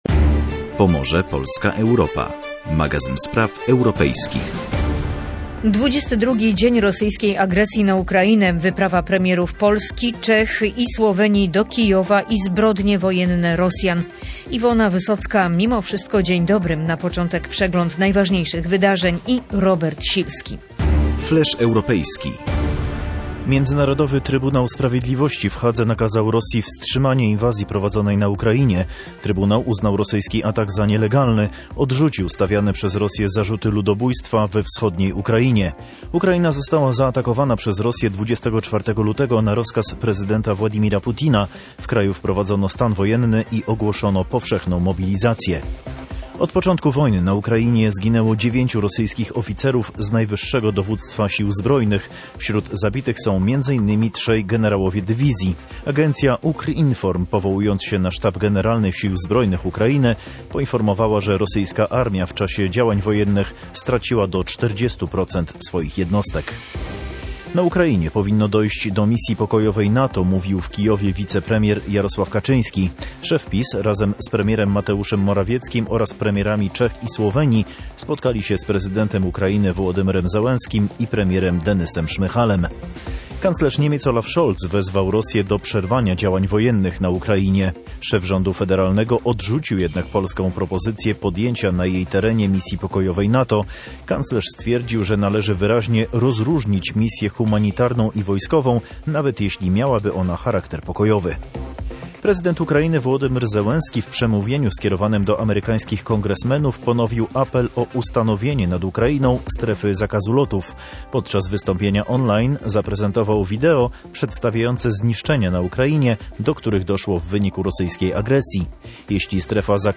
Czy jesteśmy coraz bliżej rozejmu? W audycji „Pomorze Polska Europa”